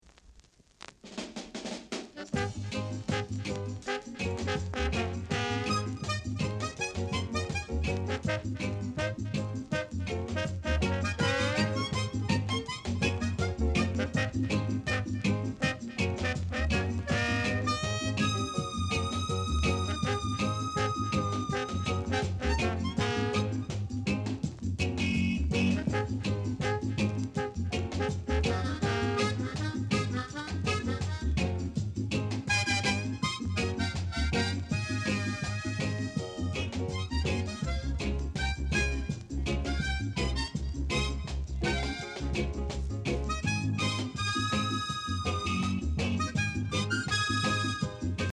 Reggae Male Vocal, Inst